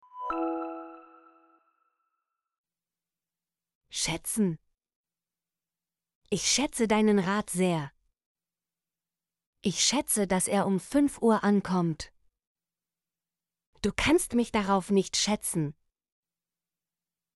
schätzen - Example Sentences & Pronunciation, German Frequency List